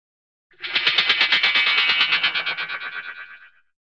Звук волшебного исчезновения на детском спектакле
• Категория: Исчезновение, пропадание
• Качество: Высокое